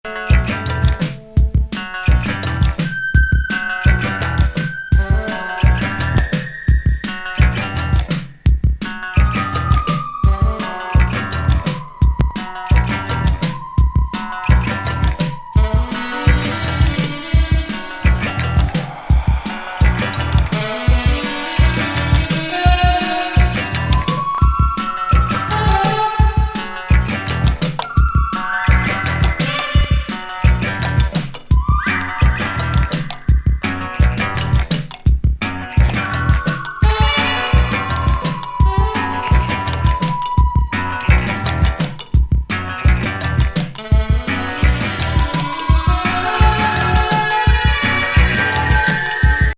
85年UKマイナーNW!エレキ・ギターのミニマル・フレーズに退廃的なサックスが
被さる、NW ELECTRO FUNK